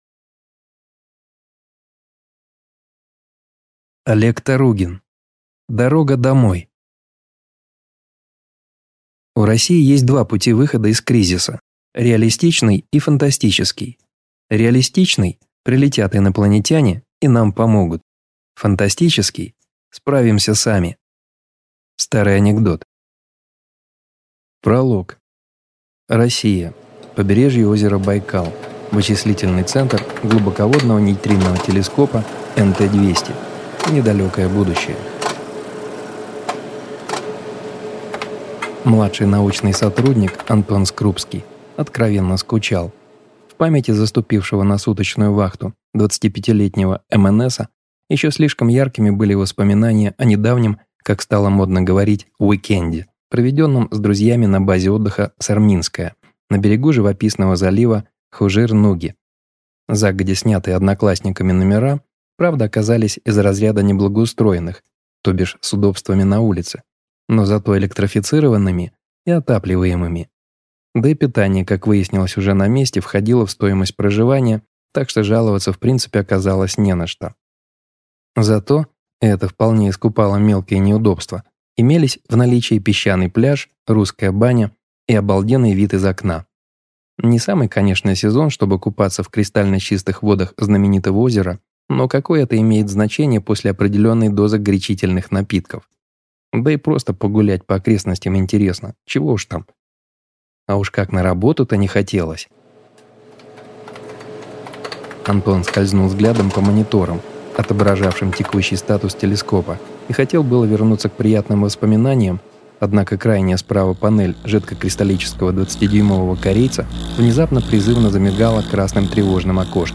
Аудиокнига Дорога домой | Библиотека аудиокниг